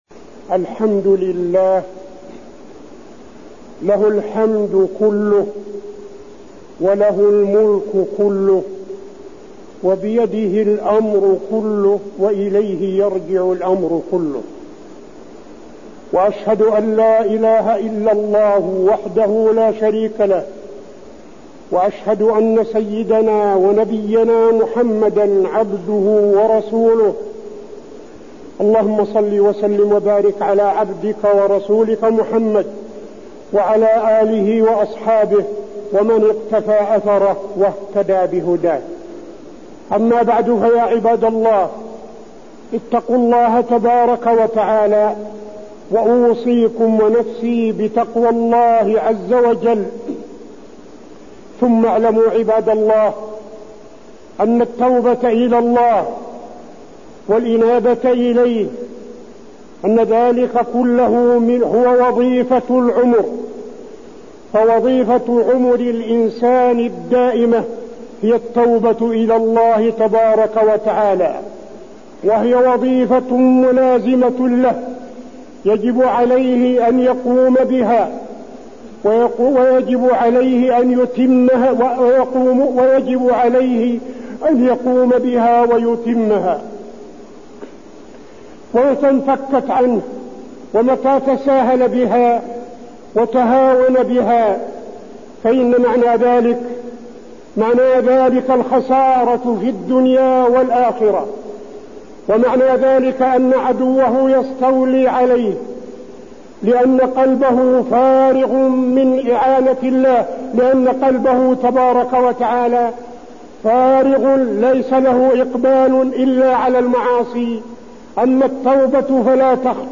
تاريخ النشر ١١ صفر ١٤٠٦ هـ المكان: المسجد النبوي الشيخ: فضيلة الشيخ عبدالعزيز بن صالح فضيلة الشيخ عبدالعزيز بن صالح التوبة